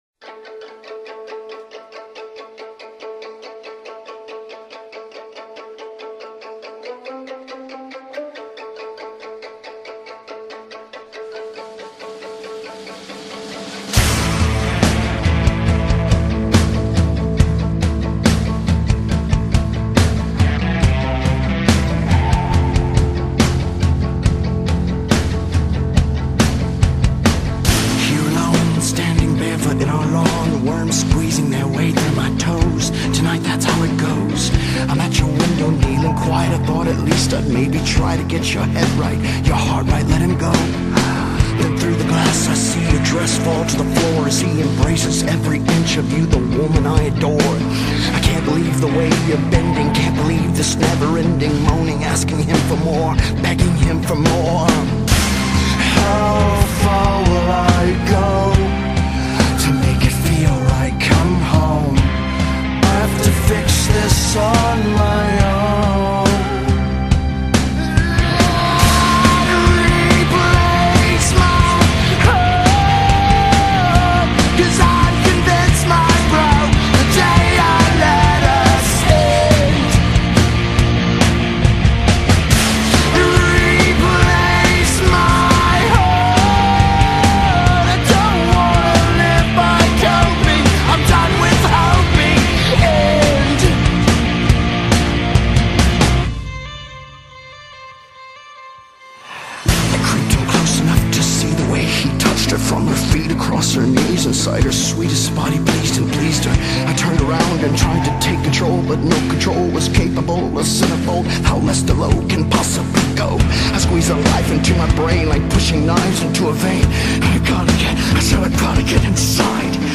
It's a little..... dark. 98c35b59eead8991.mp3 1